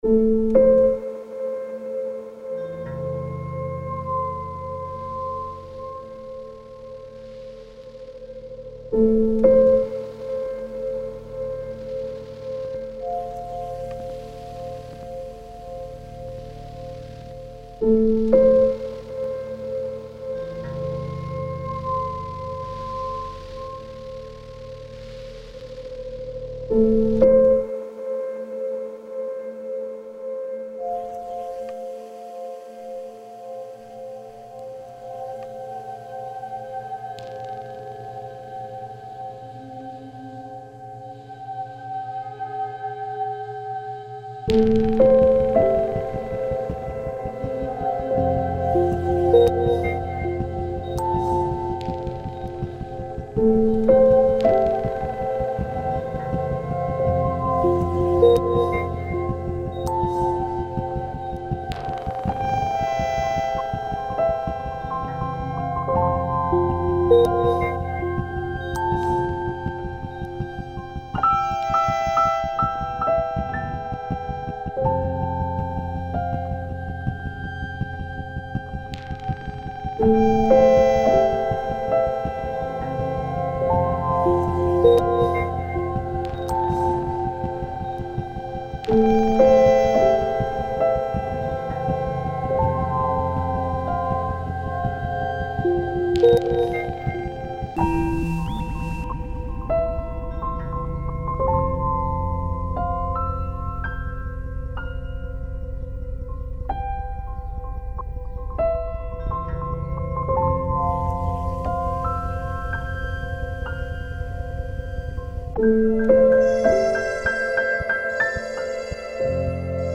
pour piano et électronique